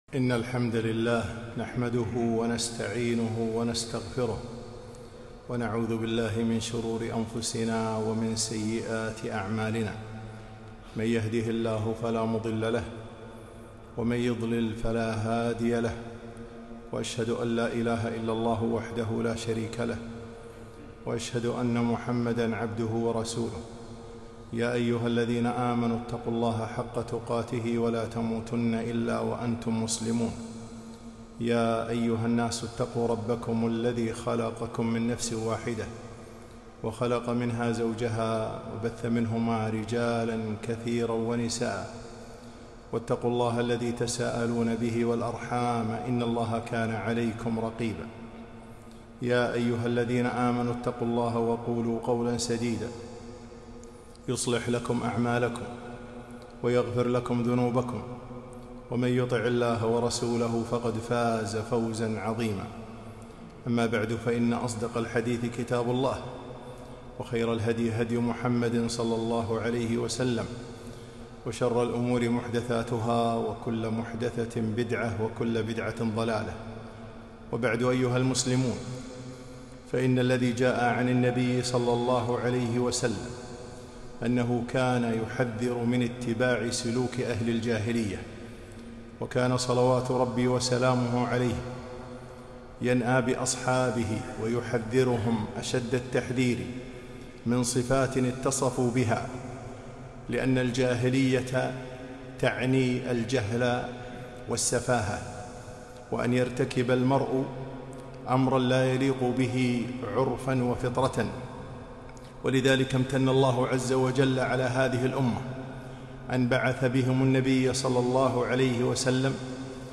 خطبة - الحذر من أخلاق الجاهلية